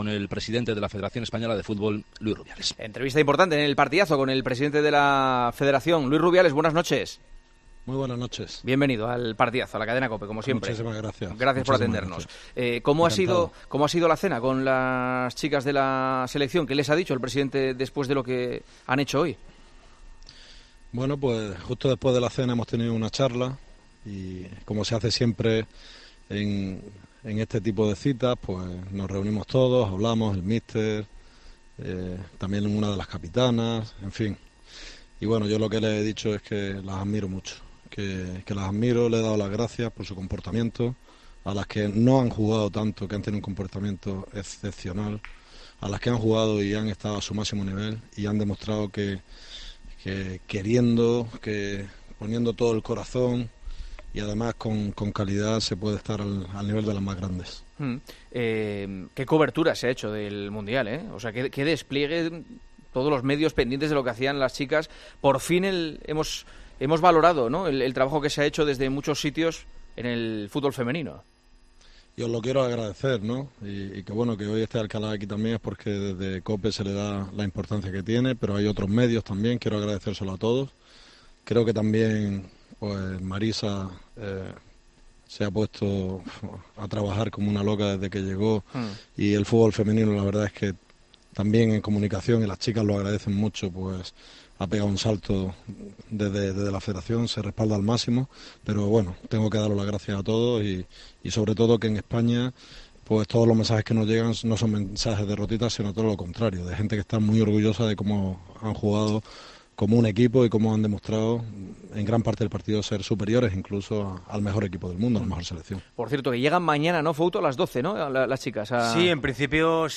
AUDIO: Hablamos con el presidente de la RFEF de la eliminación de España, el adiós de Luis Enrique a la selección y la polémica con LaLiga.